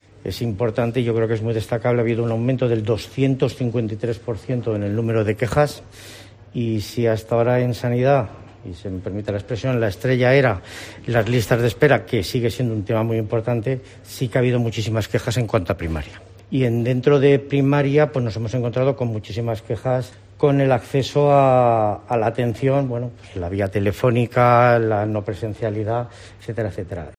El lugarteniente del Justicia, Javier Hernández, explica cuáles son las quejas que más han aumentado.